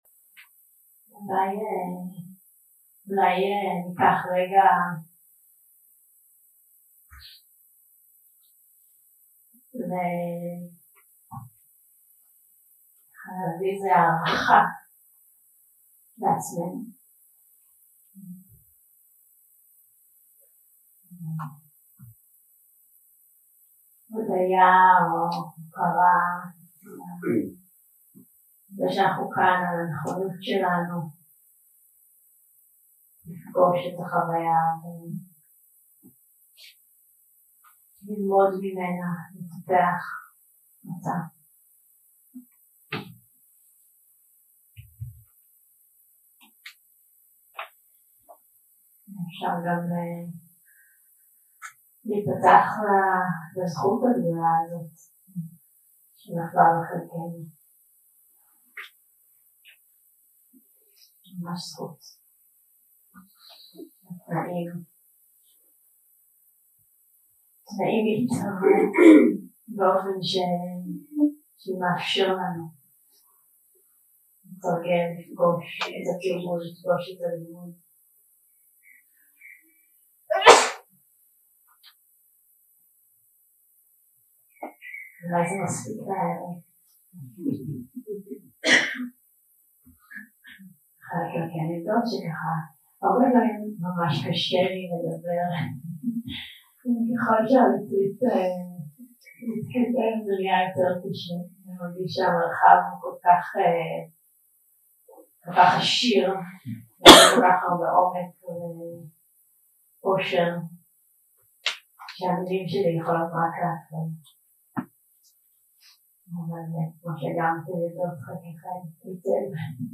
יום 5 – הקלטה 12 – ערב – שיחת דהארמה - תפיסה משחררת וריקות התופעות 0:00 0:00 סוג ההקלטה: Dharma type: Dharma Talks שפת ההקלטה: Dharma talk language: Hebrew